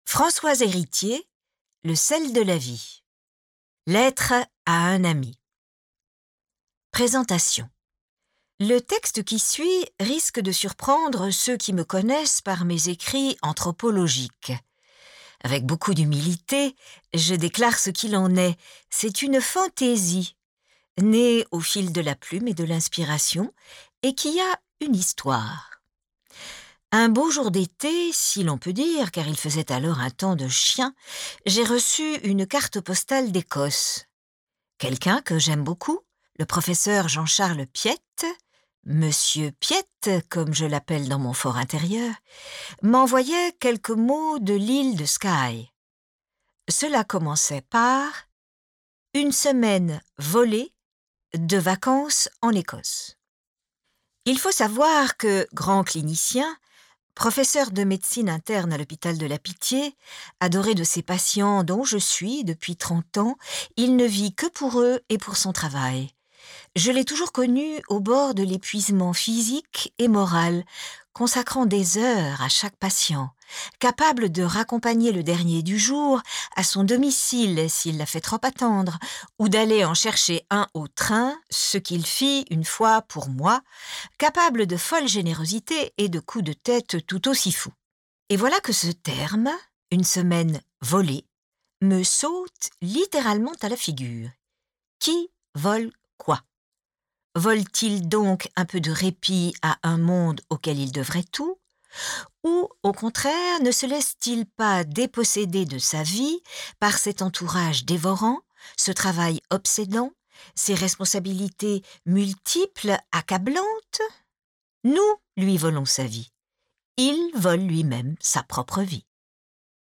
Lu par Dominique Blanc 10 , 99 € Ce livre est accessible aux handicaps Voir les informations d'accessibilité